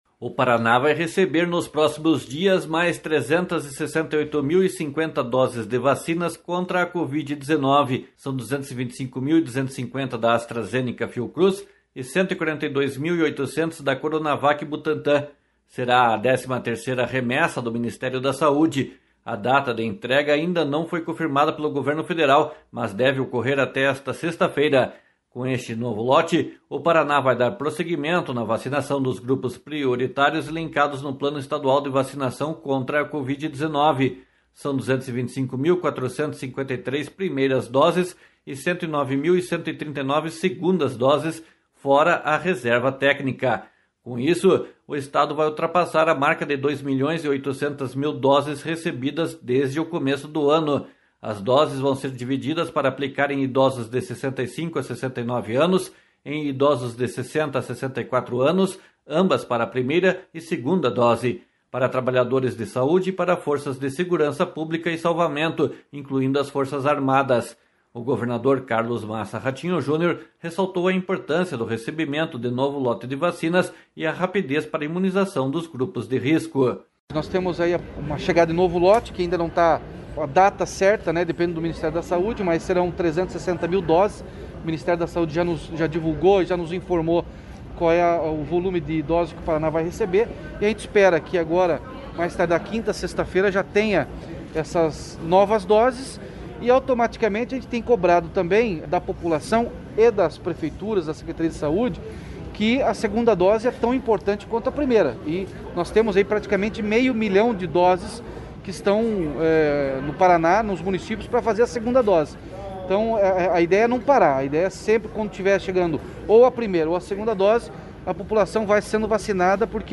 //SONORA RATINHO JUNIOR//
//SONORA BETO PRETO //